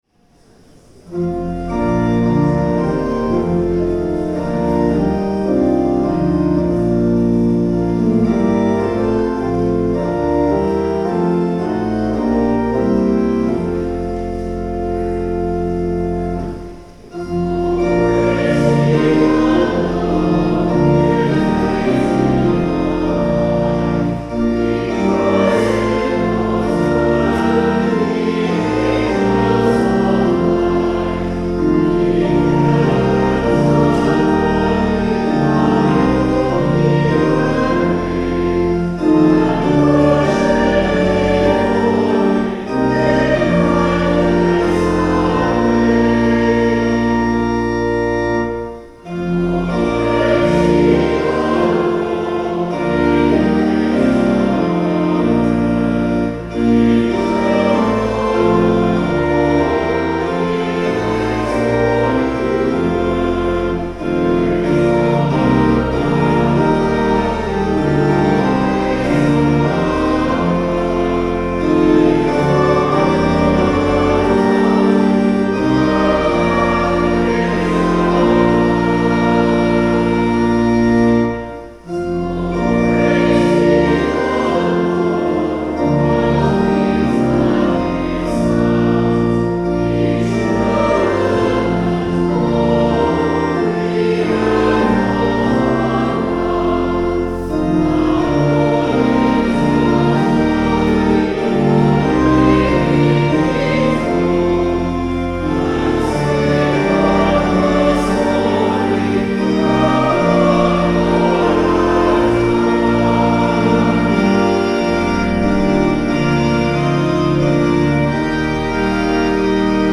Hymn
The Lord’s Prayer (sung)